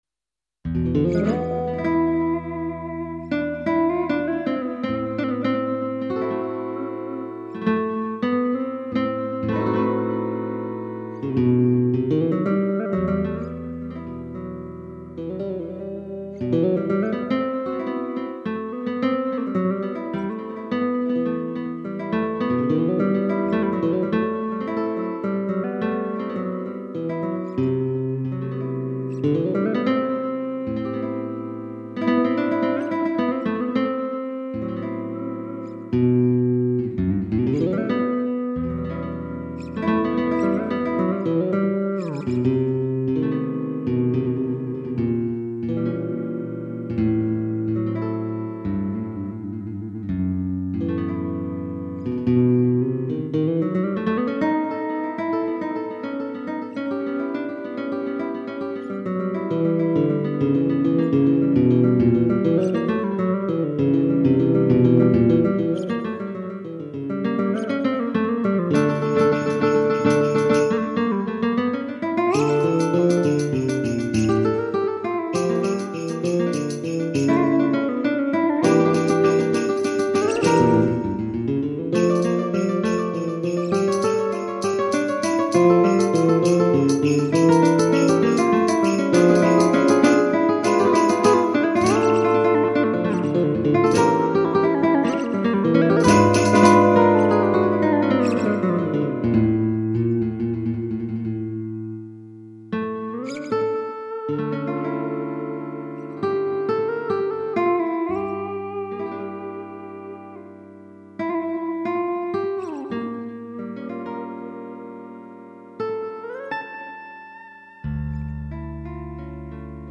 • Vortex2 с 8 Мб sf2 GM-банком: